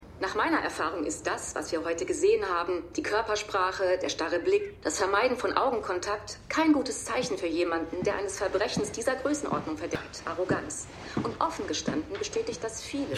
Synchronstudio: FFS Film- & Fernseh-Synchron GmbH
Verschwiegen_1x03_TVExpertin.mp3